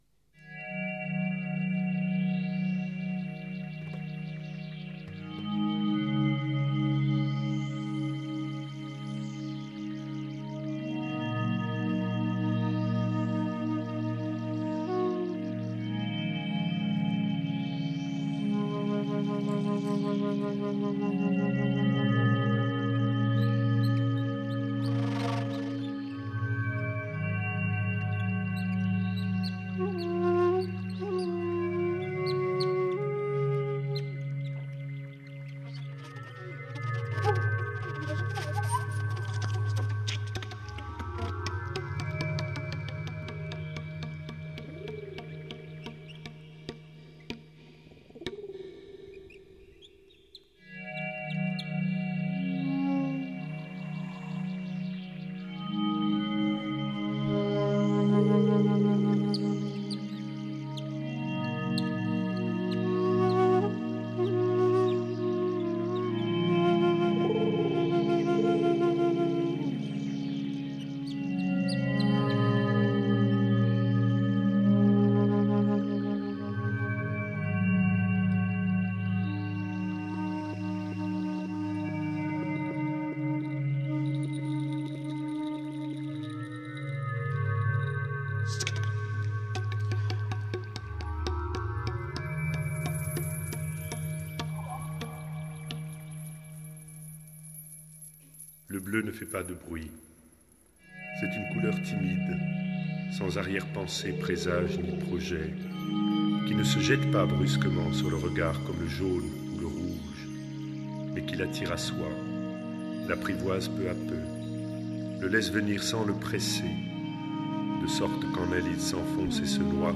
live in Carpi
tastiere, samples, chitarra, tin whistle
voce recitante
flauti e sax soprano
percussioni